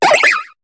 Cri d'Emolga dans Pokémon HOME.